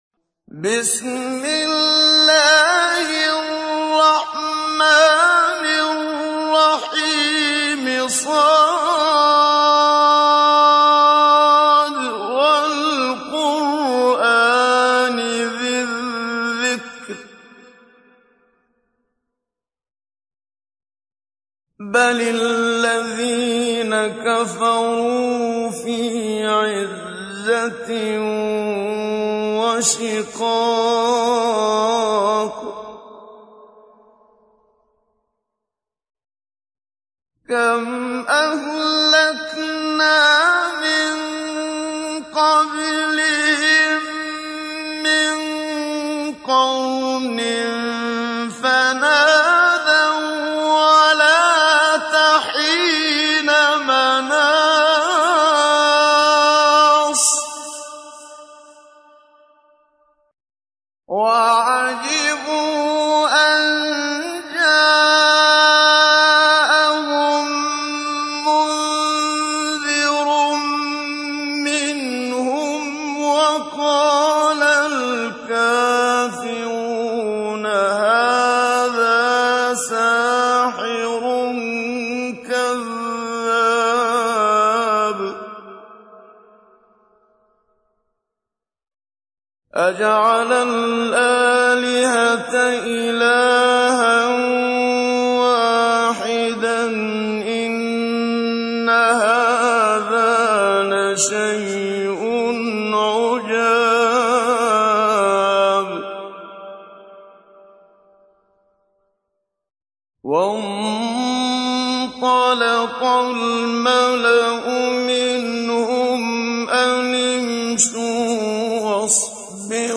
تحميل : 38. سورة ص / القارئ محمد صديق المنشاوي / القرآن الكريم / موقع يا حسين